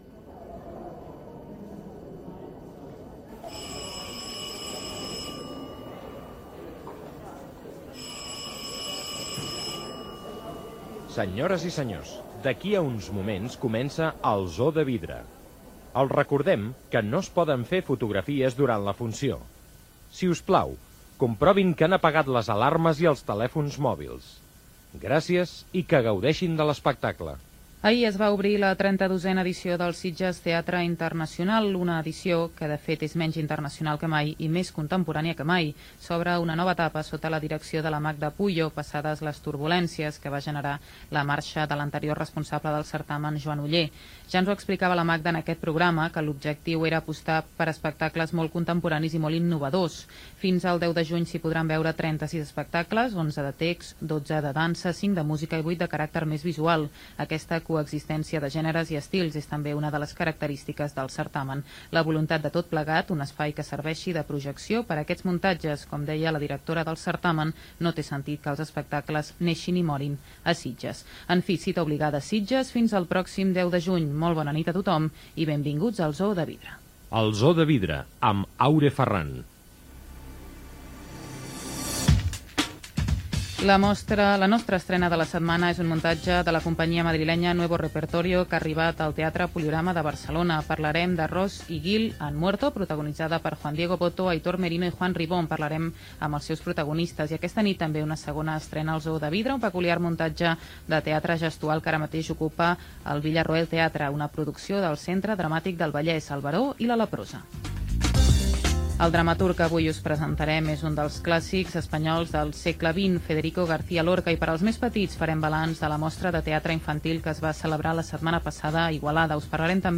Cultura
FM